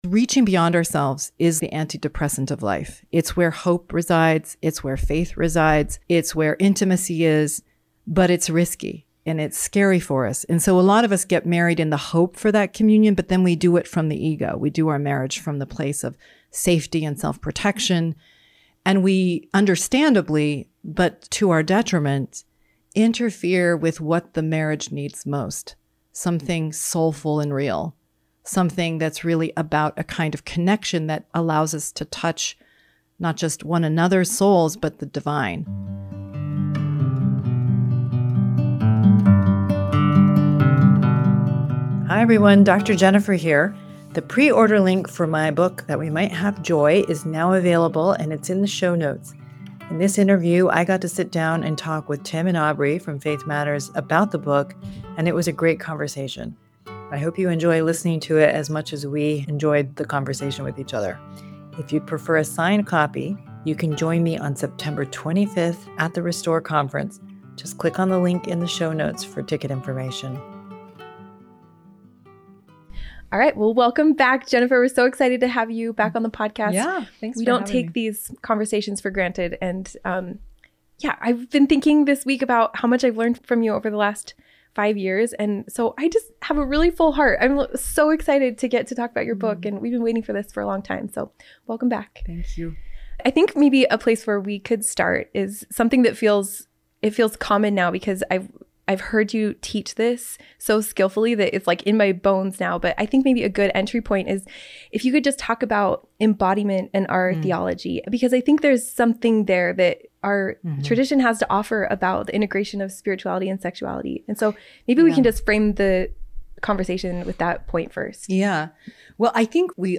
1 Desire, Divinity, and Intimate Love | A Conversation with Faith Matters 54:53